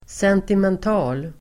Uttal: [sentiment'a:l]